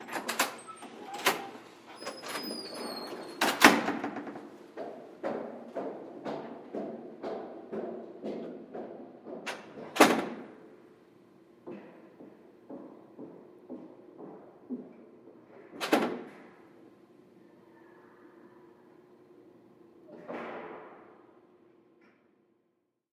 Звуки шагов по лестнице
Звук соседки, выходящей в подъезд и спускающейся по лестнице